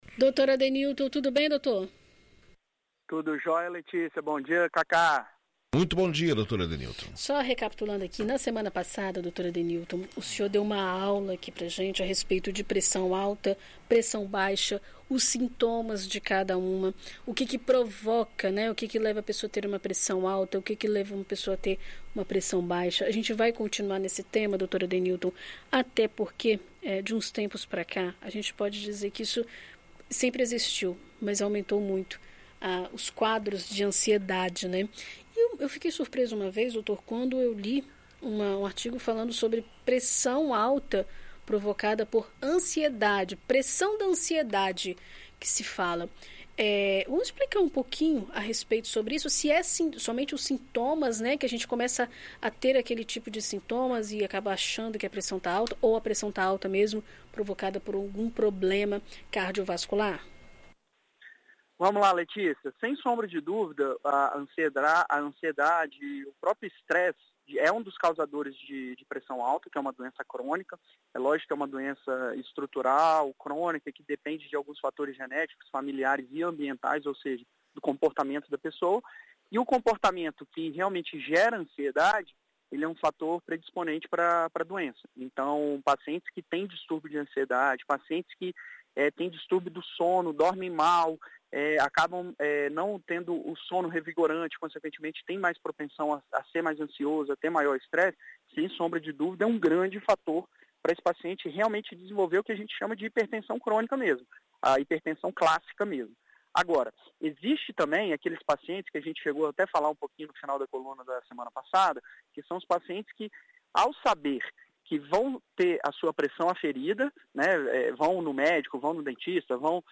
Na coluna desta semana na BandNews FM Espírito Santo